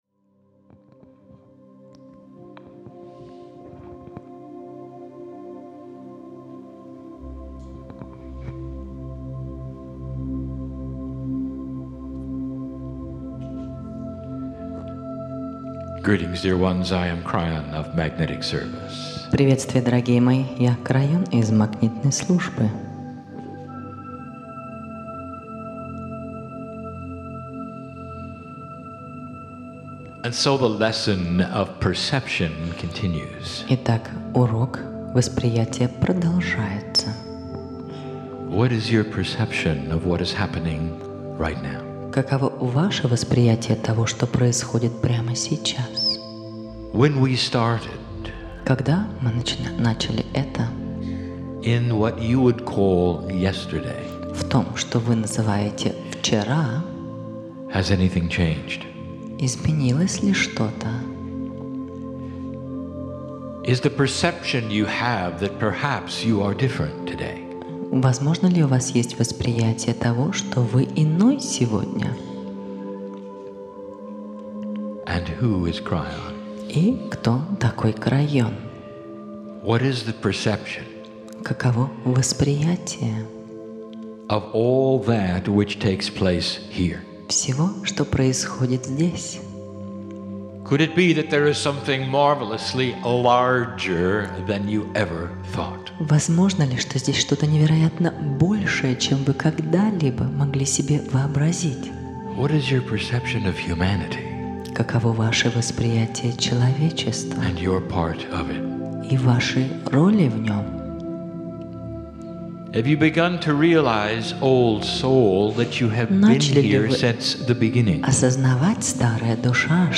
Kiev Ukraine - Three (3) Channellings